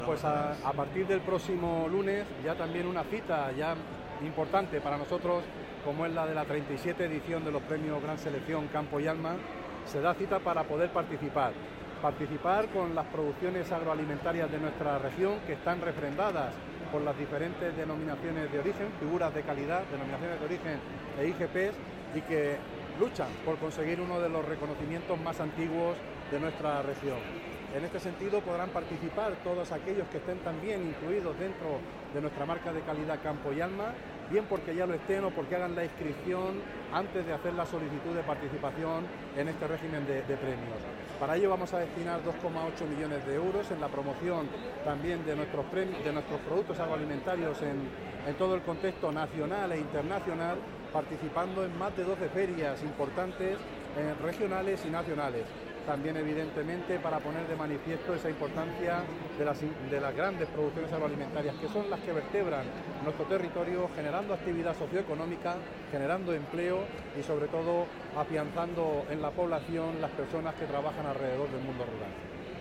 Consejería de Agricultura, Ganadería y Desarrollo Rural Viernes, 23 Enero 2026 - 3:15pm El consejero de Agricultura, Ganadería y Desarrollo Rural, Julián Martínez Lizán, ha avanzado en FITUR que el próximo lunes se publica en el DOCM la convocatoria de los Premios Gran Selección, que van por su 37ª edición.